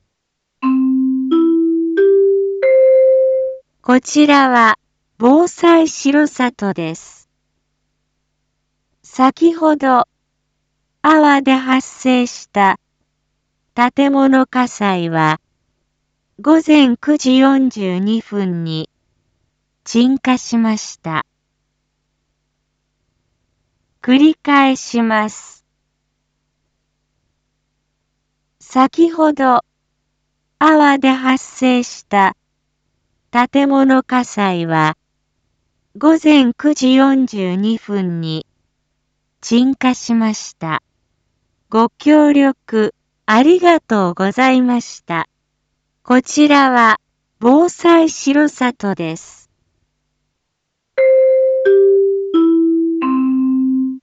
Back Home 一般放送情報 音声放送 再生 一般放送情報 登録日時：2022-07-15 09:55:55 タイトル：R4.7.15.9時55分放送分 インフォメーション：こちらは、防災しろさとです。 先ほど粟 で発生した建物火災は、午前９時４２分に鎮火しました。